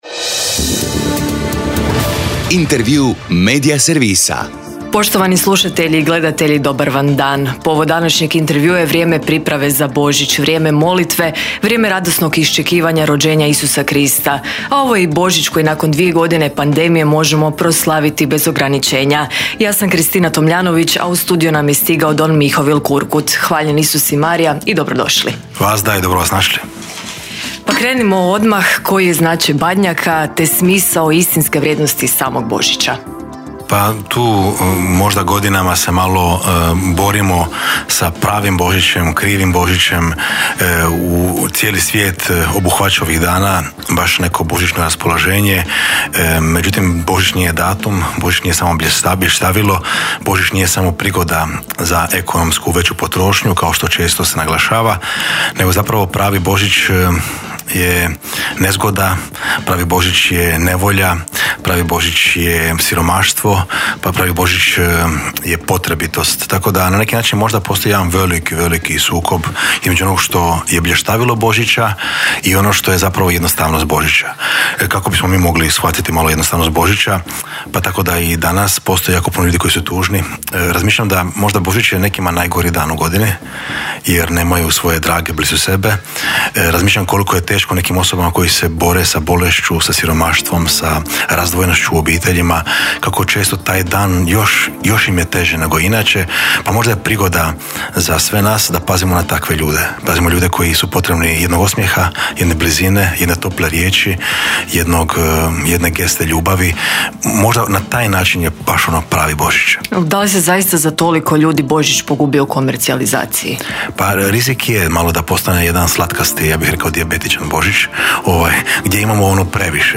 ZAGREB - Povod Intervjua tjedna Media servisa je vrijeme priprave za Božić, vrijeme molitve, vrijeme radosnog iščekivanja rođenja Isusa Krista, a ovo je i Božić koji nakon dvije godine pandemije možemo proslaviti bez ograničenja.